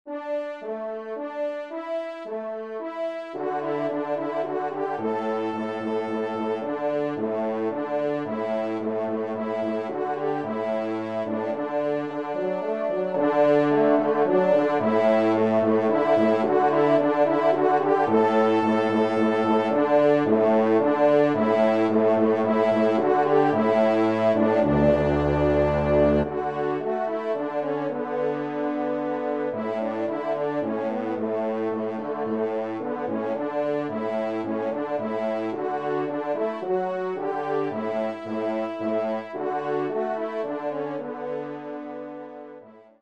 3e Trompe